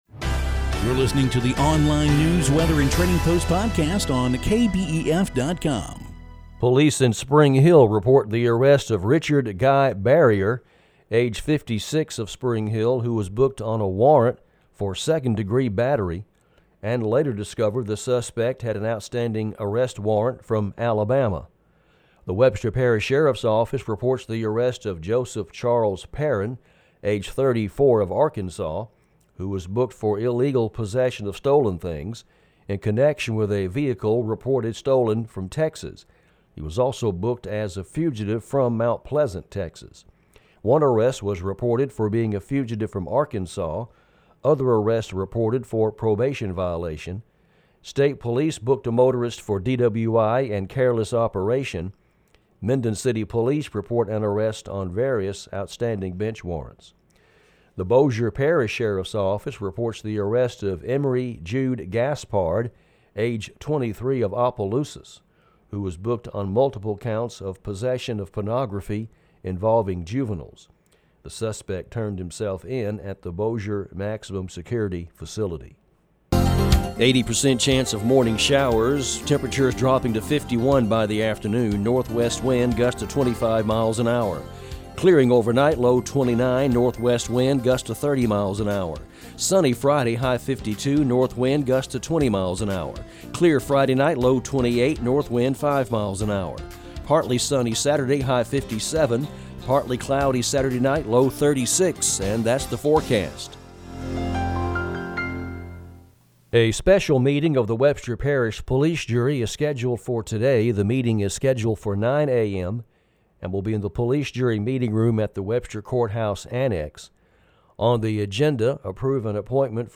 Daily news, weather and trading post podcast.